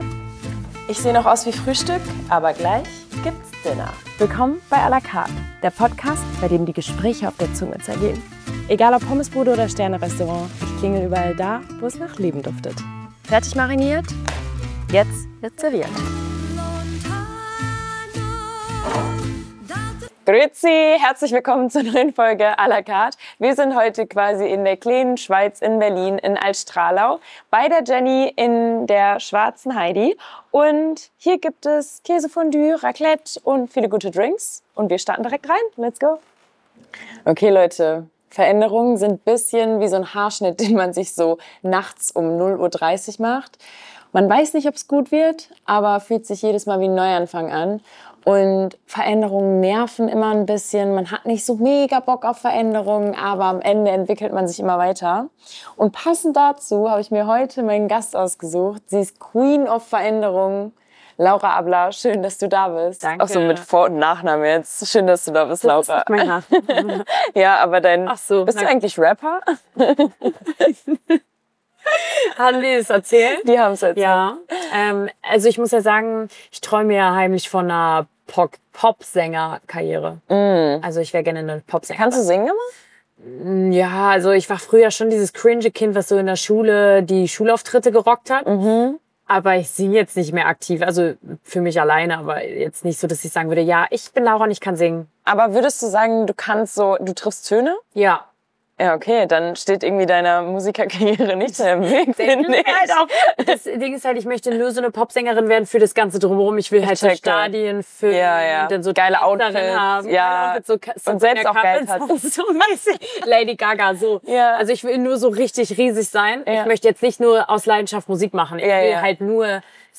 Bei Kir Royal und Fondue sprechen die beiden in der dritten Folge „à la Carte“ über Veränderung und Erfolg!